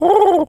pigeon_call_calm_05.wav